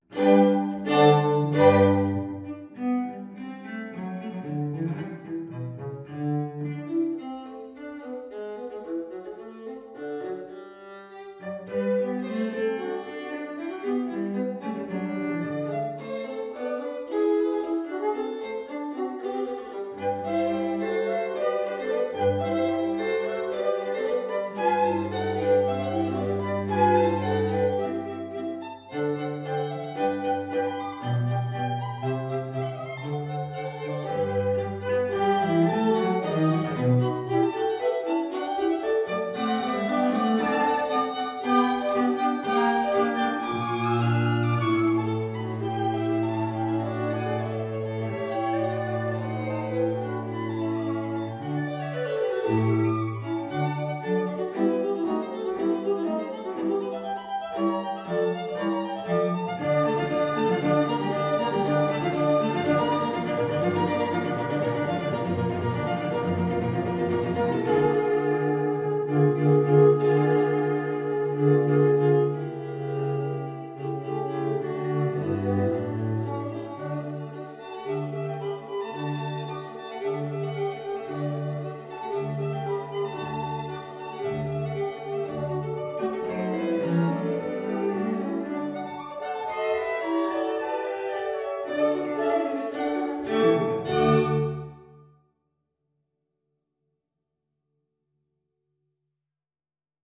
Quartet_HolyT_time_domainP1.wav